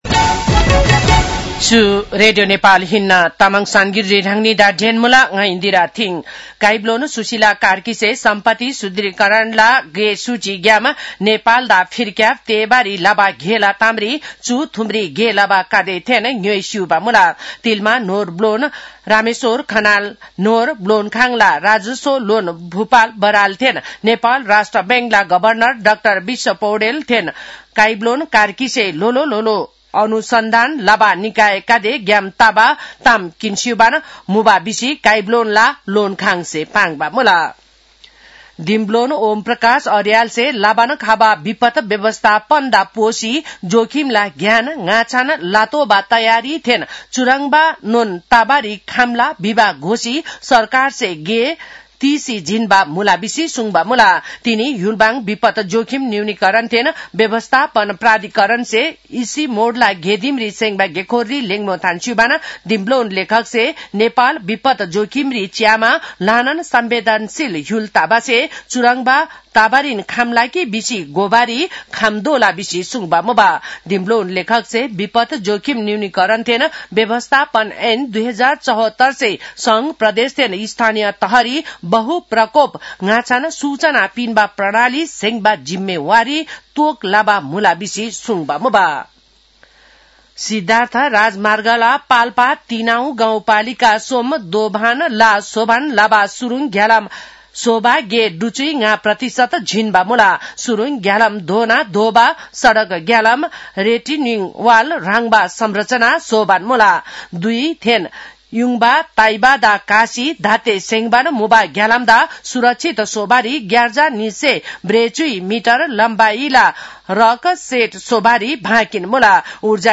तामाङ भाषाको समाचार : ७ पुष , २०८२
Tamang-news-9-07.mp3